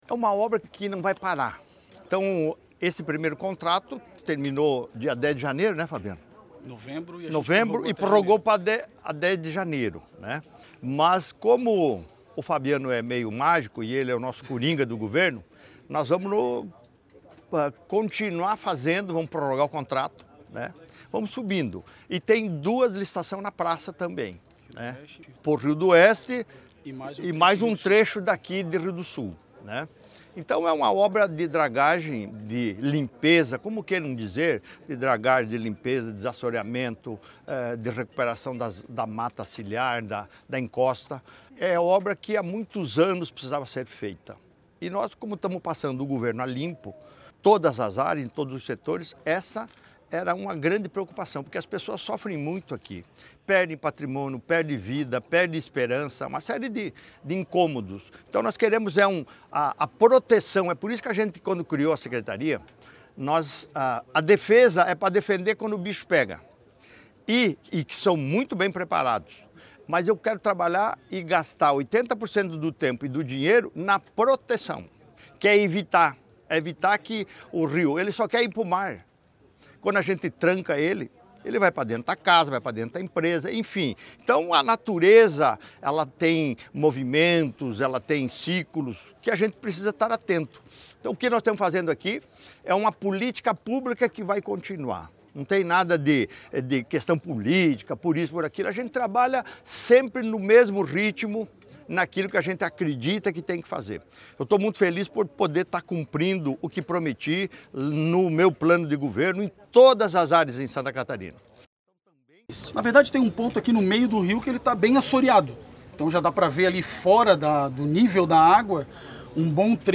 Segundo o governador é uma obra que há muitos anos precisava ser feita e que não vai parar:
O coronel também falou sobre a licitação: